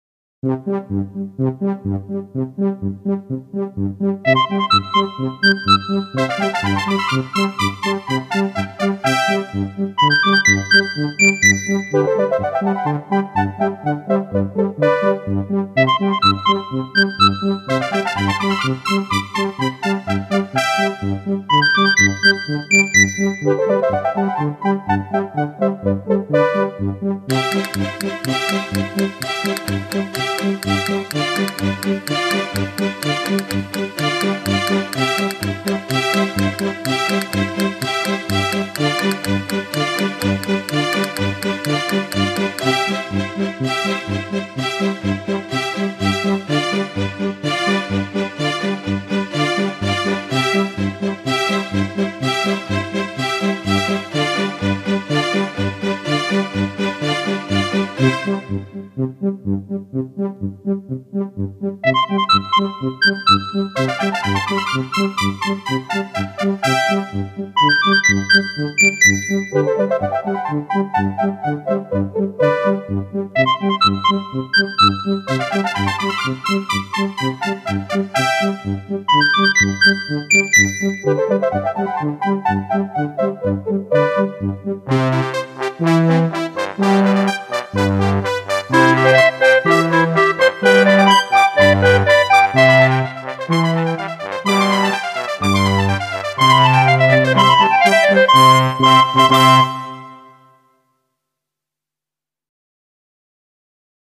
muziek van eend: waggelen